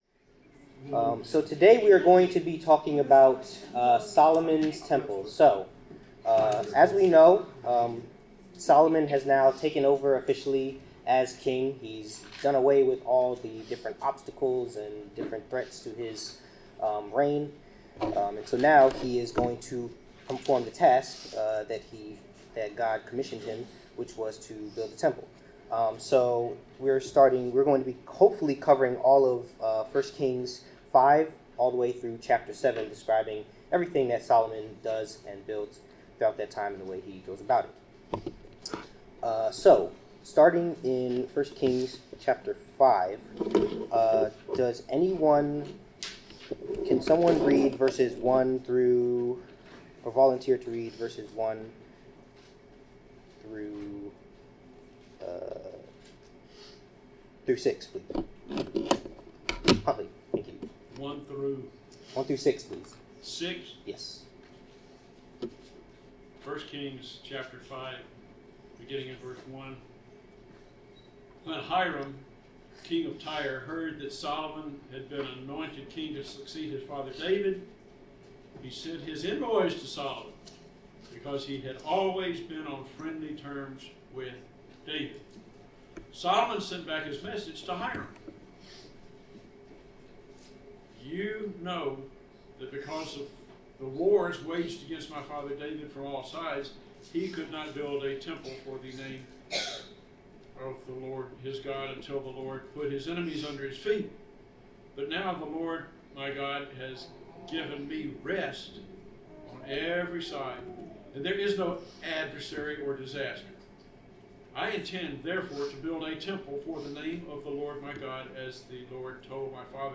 Bible class: 1 Kings 5-7 (The House of the LORD)
Service Type: Bible Class